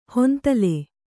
♪ hontale